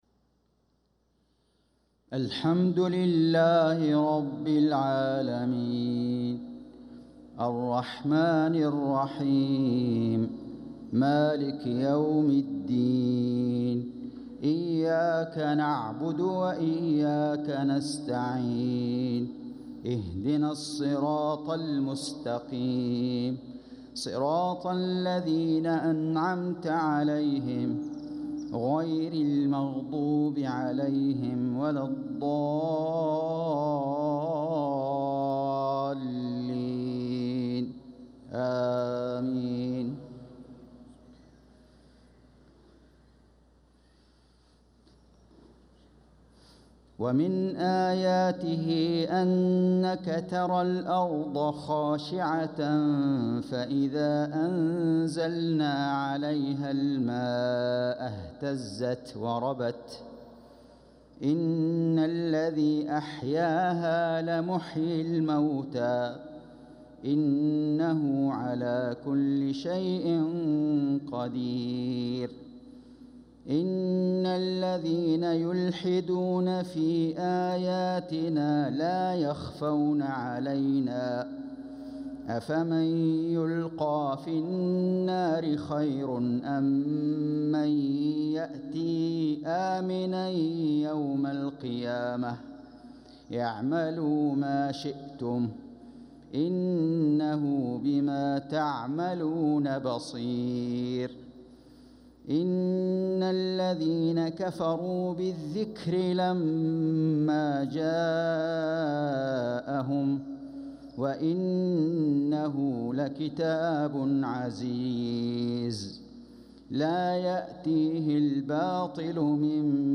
صلاة المغرب للقارئ فيصل غزاوي 9 صفر 1446 هـ
تِلَاوَات الْحَرَمَيْن .